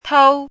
怎么读
tōu